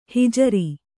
♪ hijari